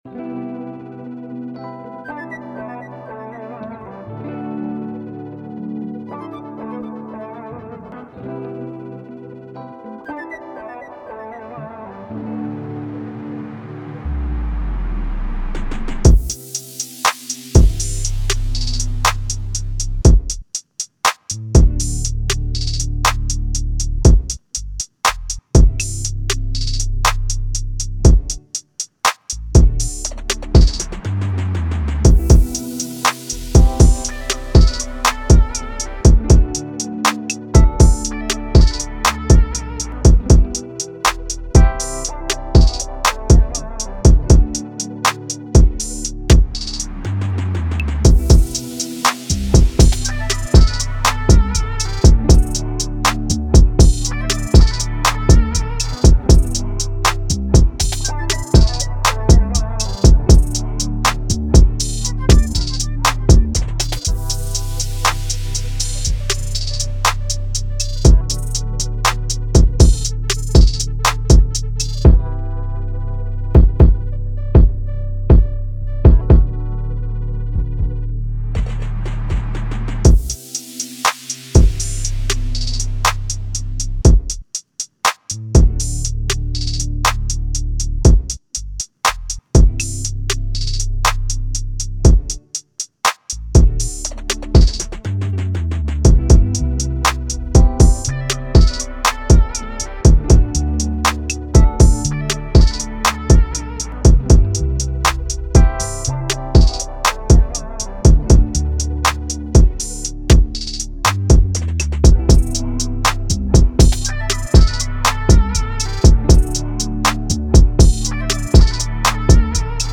R&B
D Maj